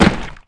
Added gib impact sounds (Droplets compat).